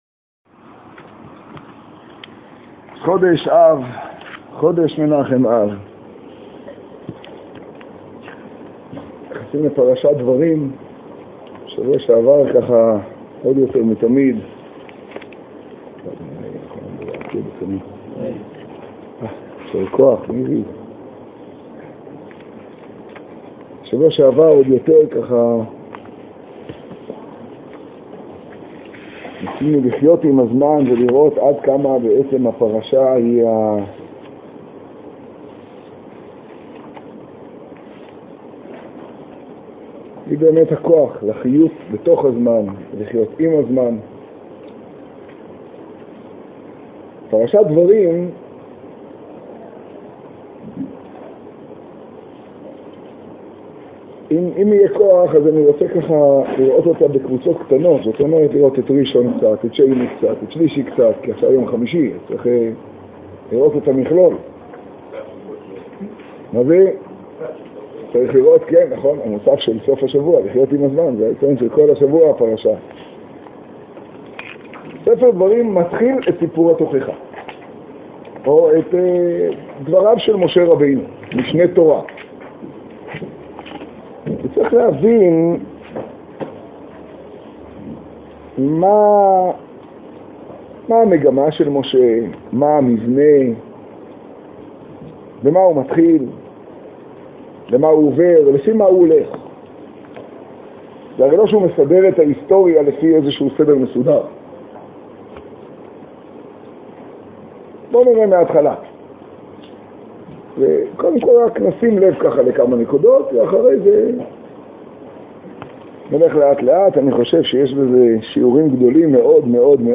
השיעור במגדל, פרשת דברים תשעד.
האזנה / קריאה קטגוריה: שיעור , שיעור במגדל , תוכן תג: דברים , חומש , תשעד → ייתי ואזכי דאיתיב בטולא דכופיתא דחמריה ממ"ד: מטות, מסעי, דברים ←